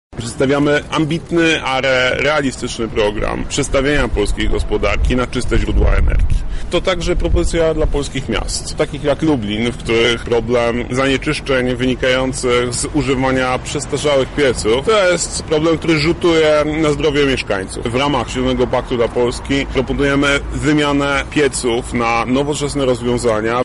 „Ta sprawa dotyczy każdego z nas” – przekonywał Adrian Zandberg podczas wizyty w Lublinie.
O tym, jak będzie realizowany w naszym mieście, mówi warszawski kandydat na posła z ramienia Lewicy Adrian Zandberg: